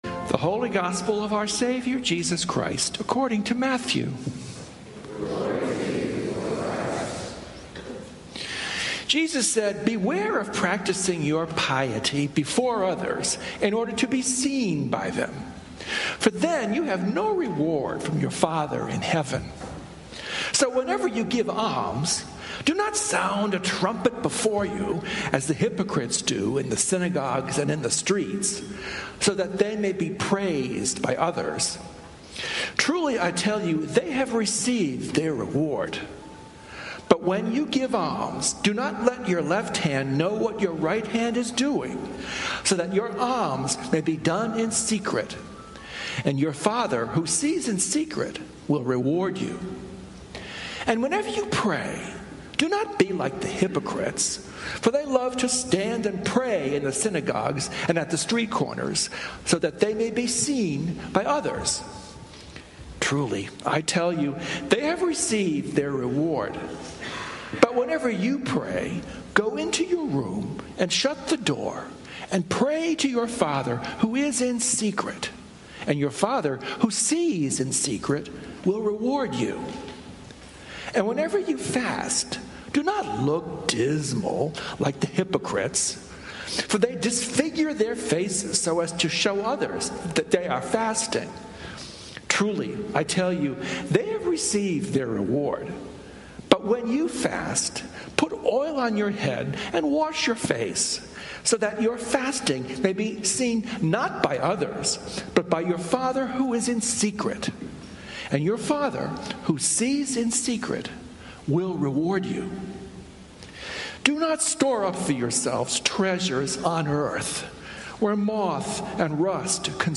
Sermons from St. Columba's in Washington, D.C.